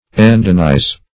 Endenize \En*den"ize\